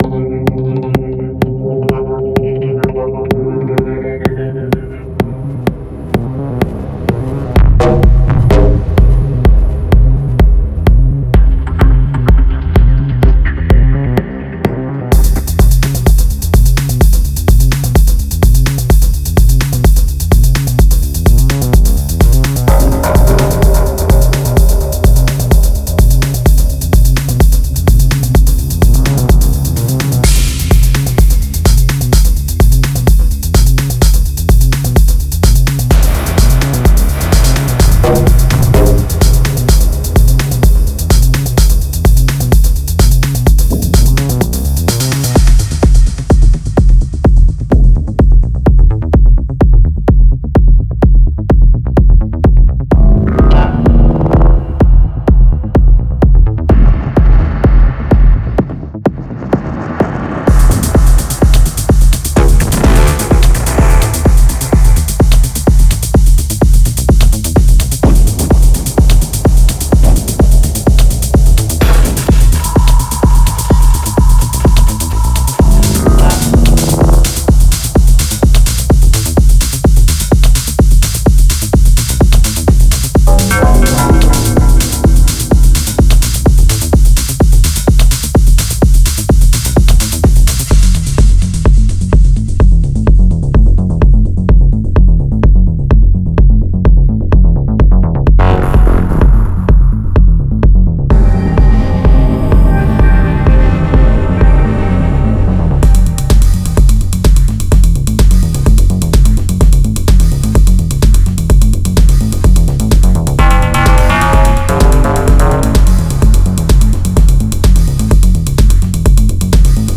Tech House Techno
127 BPM
48 Synth Loops (Dry & Wet)